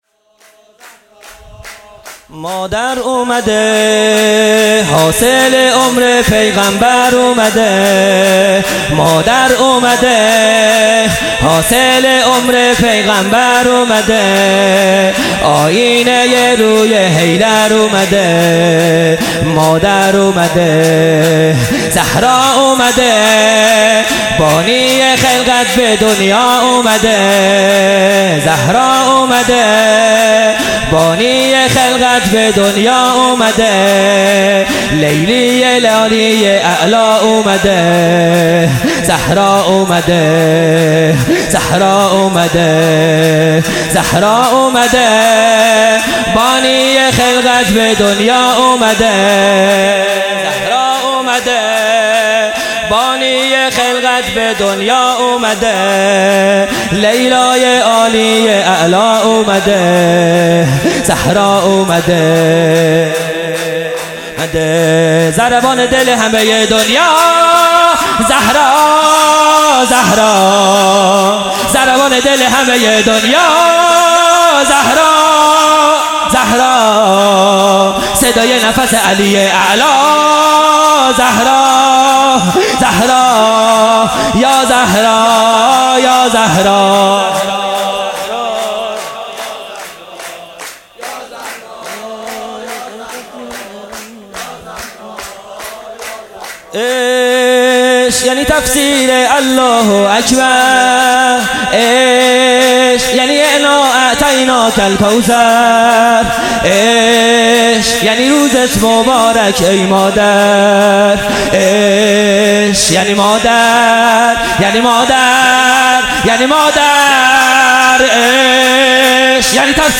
خیمه گاه - هیئت بچه های فاطمه (س) - سرود|پنج شنبه ۱۶ بهمن ۹۹